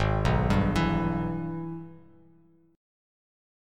G#mM7#5 chord